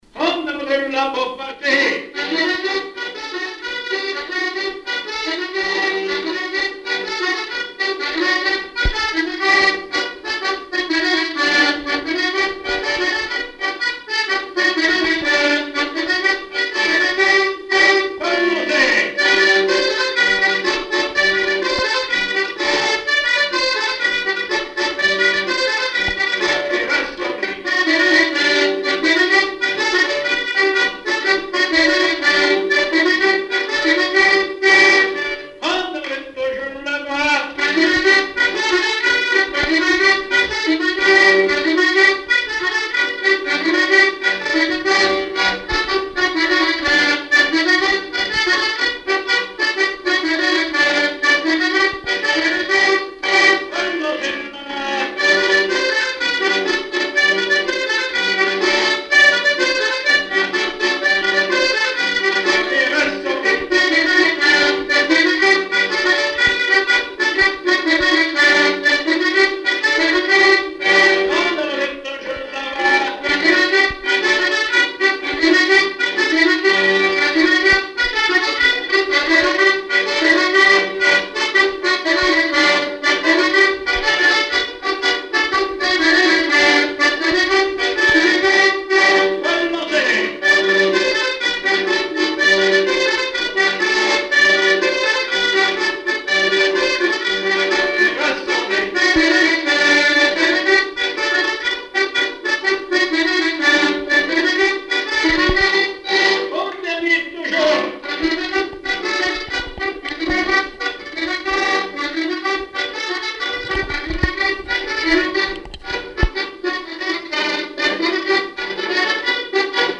En avant-deux
danse : branle : avant-deux
Répertoire du violoneux
Pièce musicale inédite